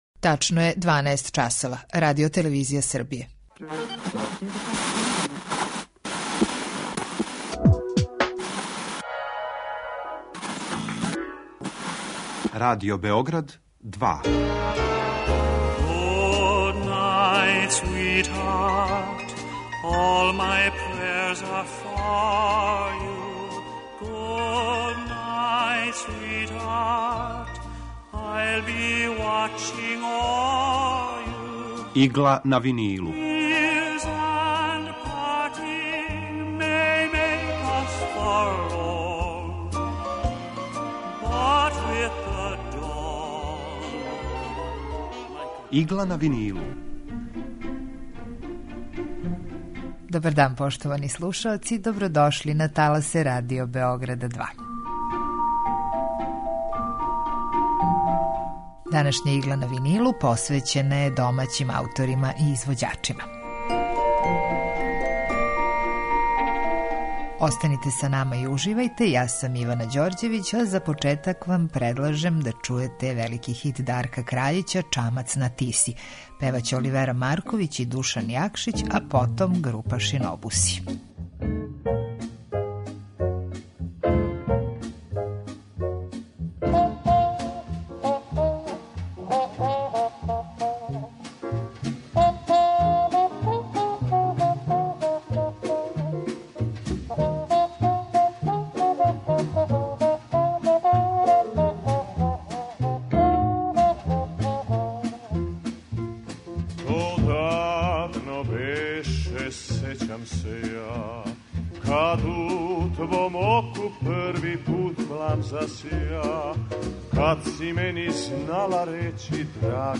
Евергрин музика